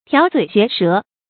調嘴學舌 注音： ㄊㄧㄠˊ ㄗㄨㄟˇ ㄒㄩㄝˊ ㄕㄜˊ 讀音讀法： 意思解釋： 調嘴：耍嘴皮。